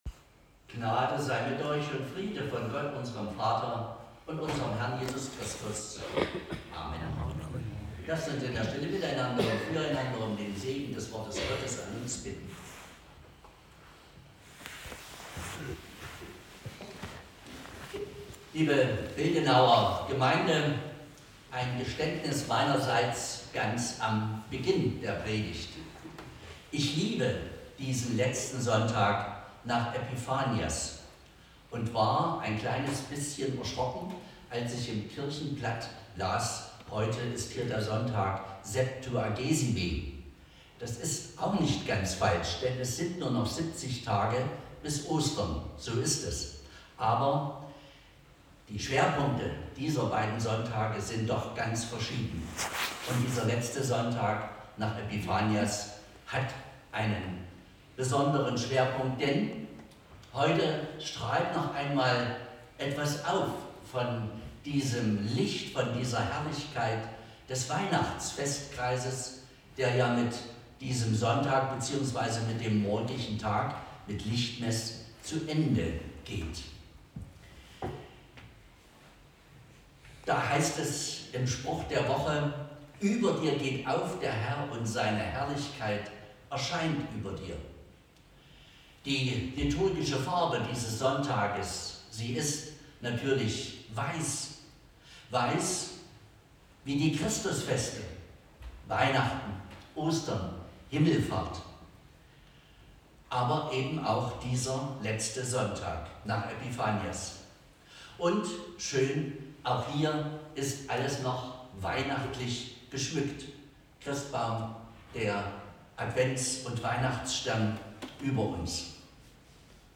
Passage: Offenbarung 1 Gottesdienstart: Predigtgottesdienst Wildenau « Vom reichen Mann und armen Lazarus Wir haben hier keine bleibende Stadt!